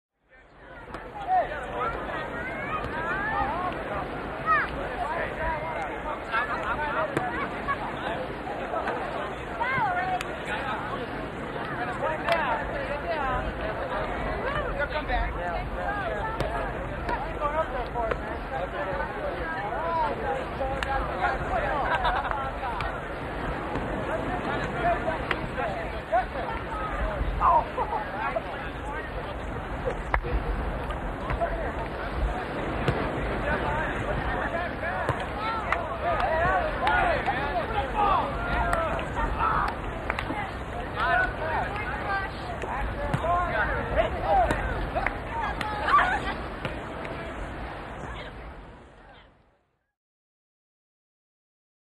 Walla, Beach | Sneak On The Lot
Small Beach Volleyball Crowd Idle With Surf In Background